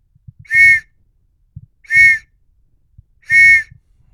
경찰 호루라기 소리